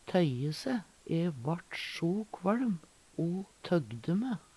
tøye se - Numedalsmål (en-US)